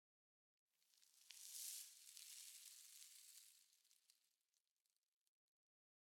sounds / block / sand
sand14.ogg